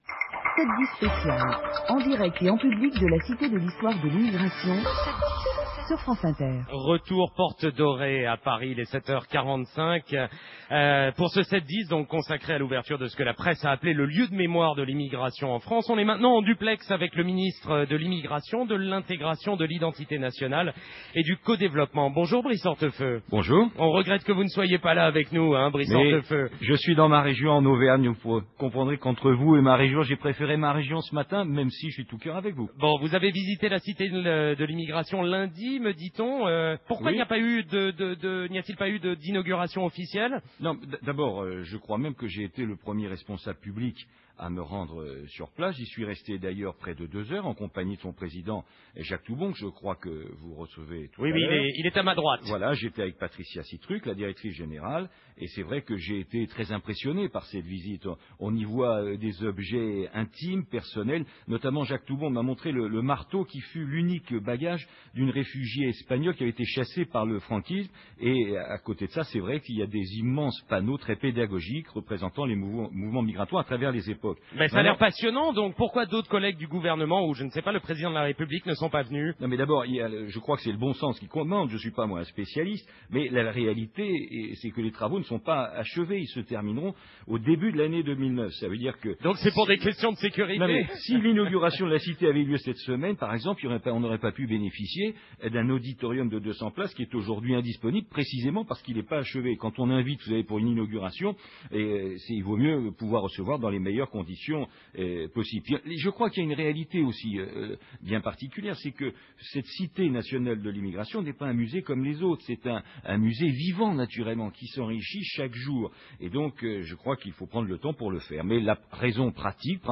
- Interview de Brice Hortefeux par Nicolas de Morand (suivie de la Chronique de Philippe Val… j’ai pas coupé !)